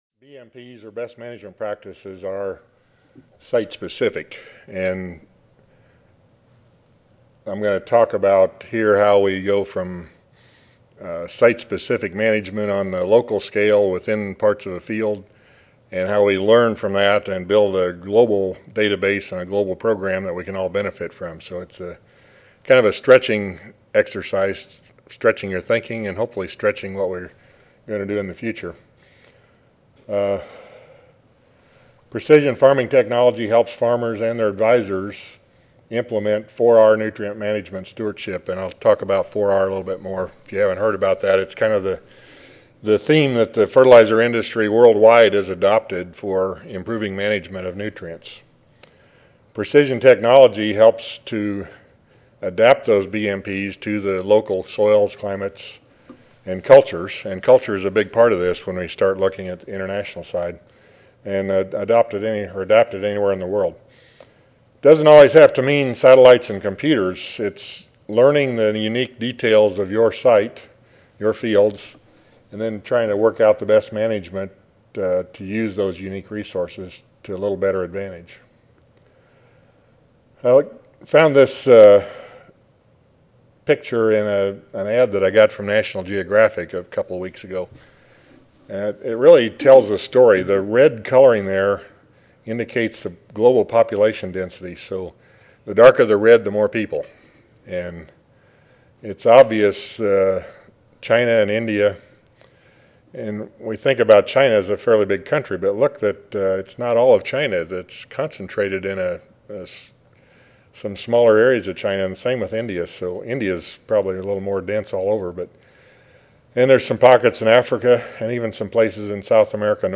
Audio File Recorded presentation 1:30 PM Div.